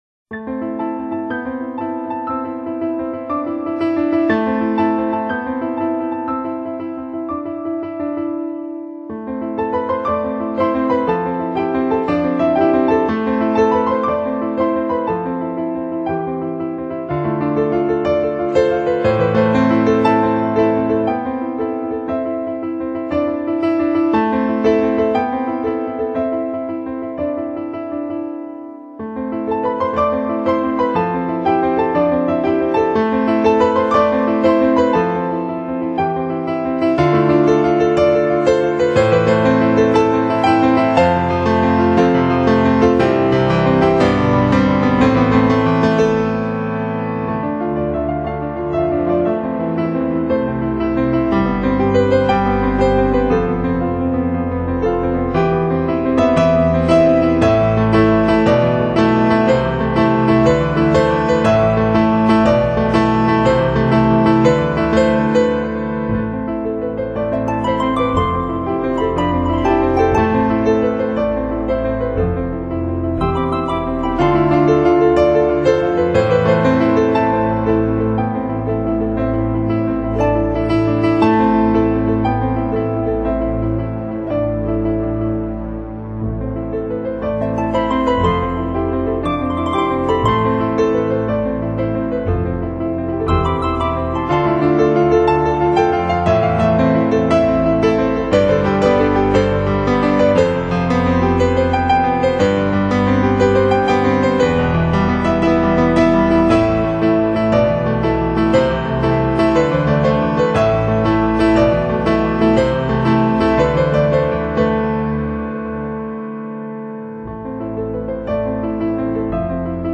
主题鲜明的钢琴演奏专辑，琢磨出钢琴静谧、激情的双面美感
★收放自如的钢琴飨宴